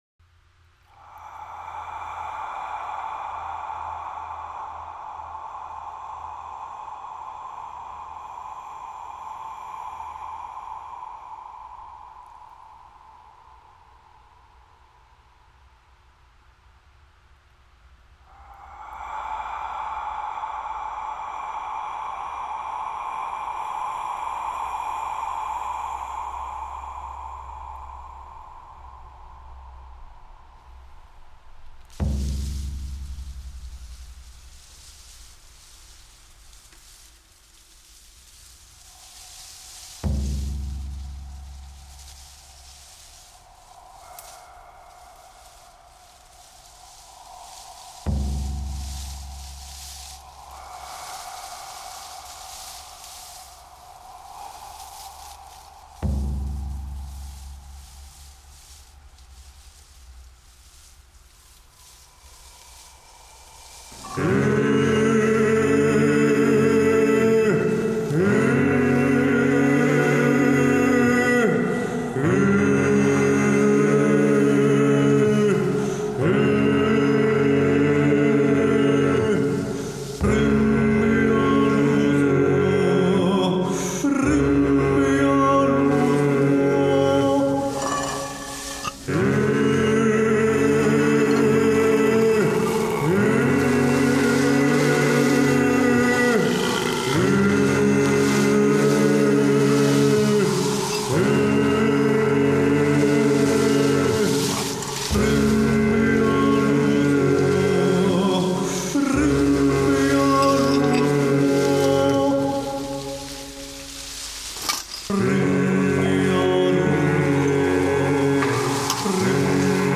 BREATH SUN BONE BLOOD FESTIVAL A GATHERING OF ANTIFASCIST ANIMISTIC BLACK METAL AND DARK AMBIENCE 14.